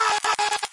普通科幻 " 失灵的机器人1
描述：一个故障的机器人。
Tag: 机电 电子 机械 人工 计算机 机械 科幻小说 机器人 机器人出现故障 发生故障的机器人 故障 科幻 机器人技术 编辑